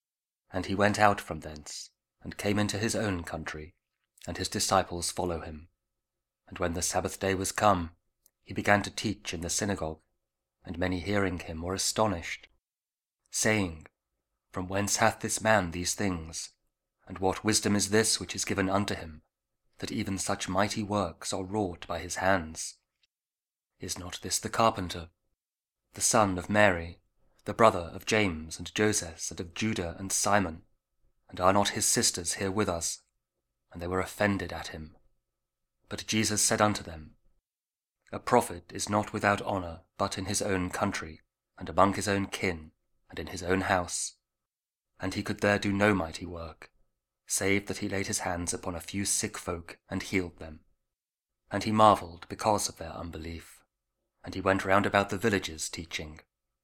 Mark 6: 1-6 – Week 4 Ordinary Time, Wednesday (Audio Bible KJV, Spoken Word)